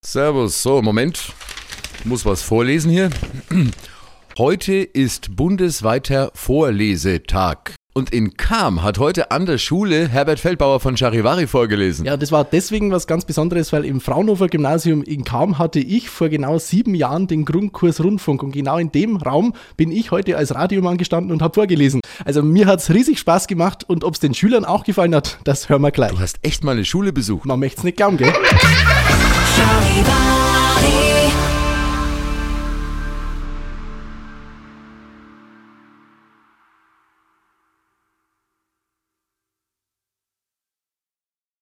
Vorlesetag am Fraunhofer-Gymnasium